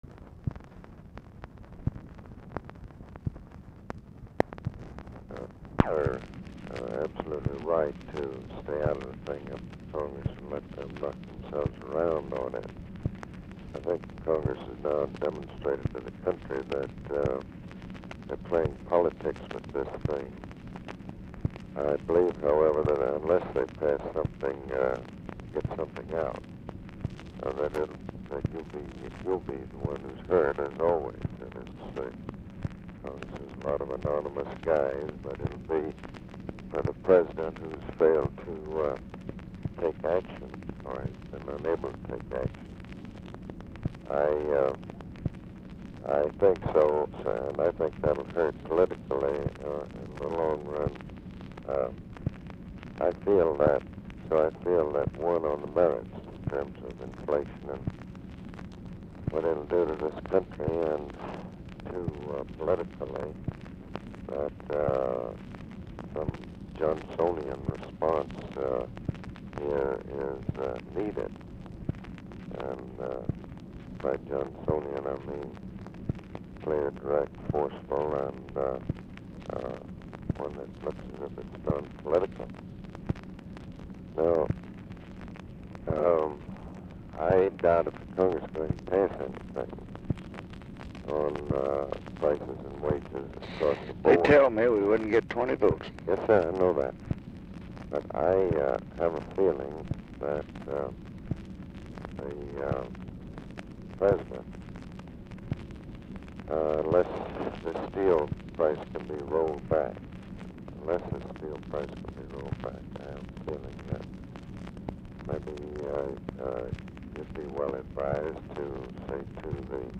RECORDING STARTS AFTER CONVERSATION HAS BEGUN; LBJ INTERRUPTS CONVERSATION AT TIMES TO LISTEN TO TV NEWS; CONTINUES ON NEXT RECORDING
Format Dictation belt
Specific Item Type Telephone conversation